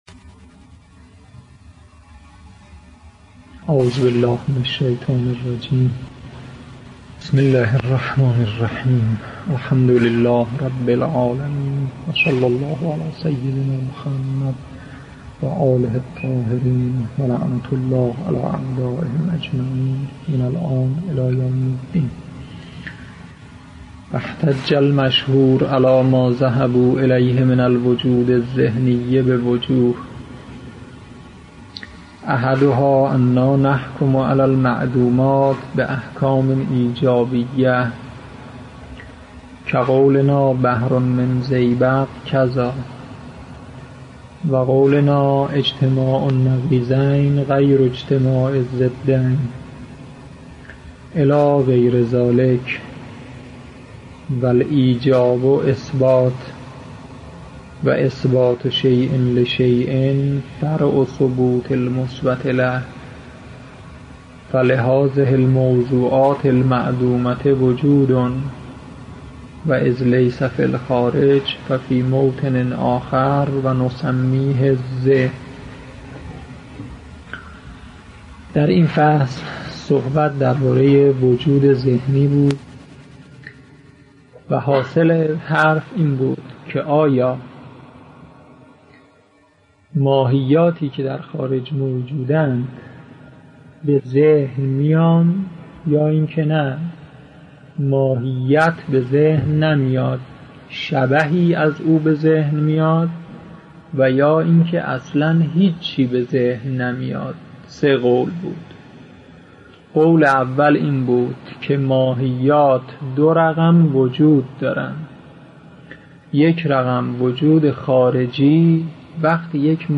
در این بخش، کتاب «بدایة الحکمة» که می‌توان دومین کتاب در مرحلۀ شناخت علم فلسفه دانست، به صورت ترتیب مباحث کتاب، تدریس می‌شود. صوت‌های تدریس متعلق به آیت الله شیخ غلامرضا فیاضی است.